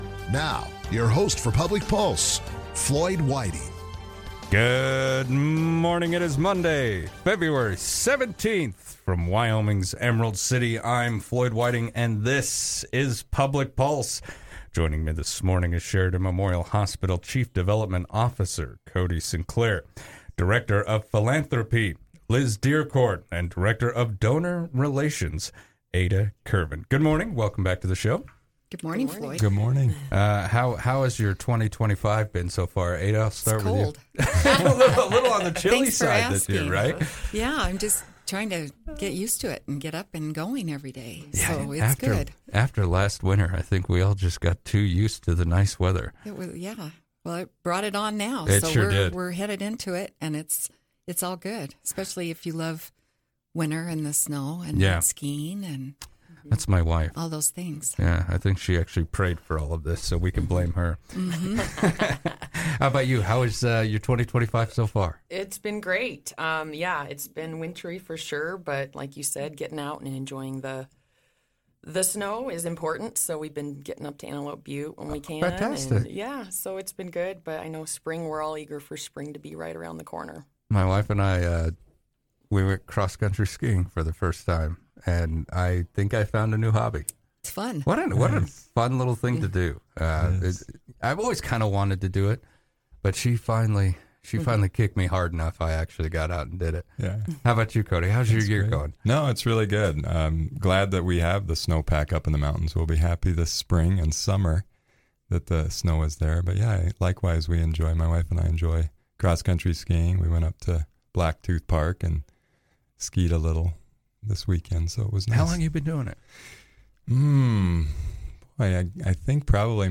Sheridan Memorial Hospital Foundation leaders recently joined Sheridan Media’s Public Pulse to share updates on the Growing for You campaign.